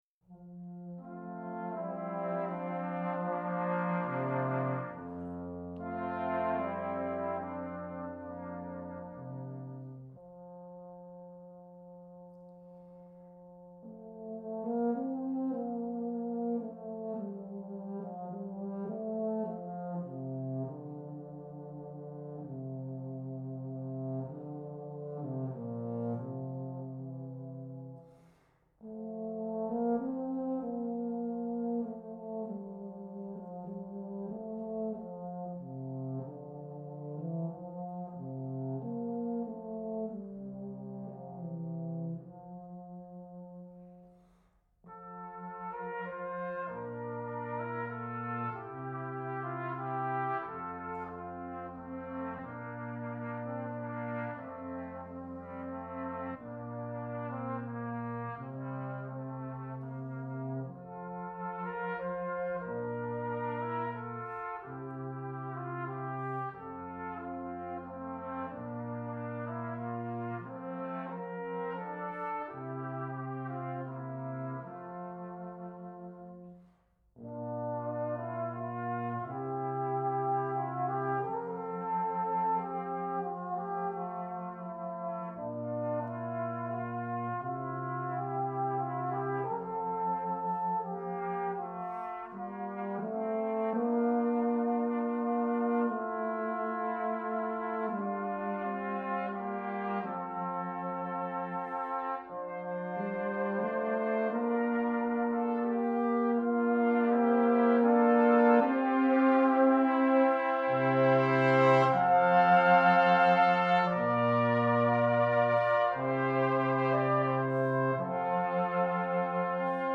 Ensemble 4 voix Flex
Young Band/Jugend Band/Musique de jeunes